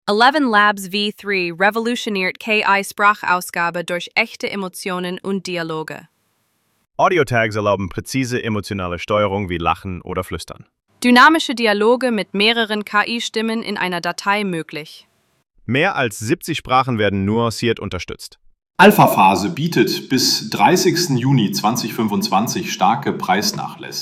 Genau das verspricht ElevenLabs mit der Einführung von Eleven v3, dem bisher ausdrucksstärksten Text-to-Speech-Modell des Unternehmens.